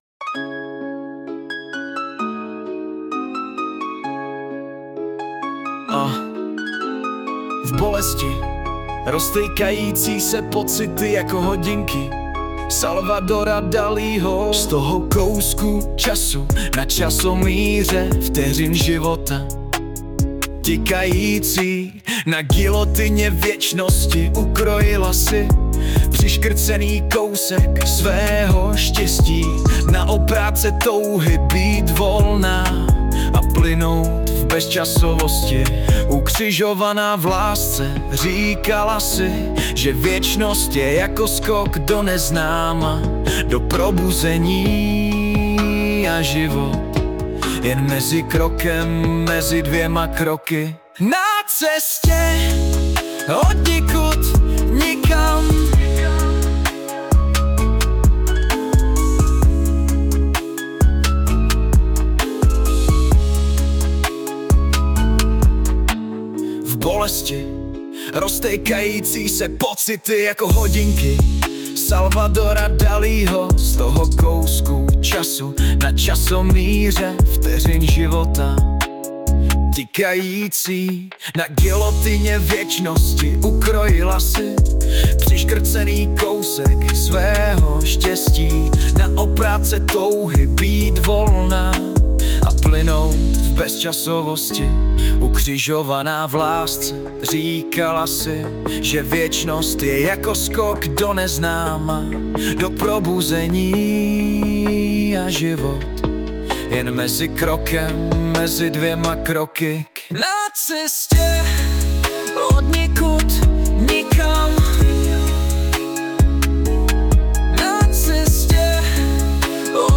2018 & Hudba a Zpěv: AI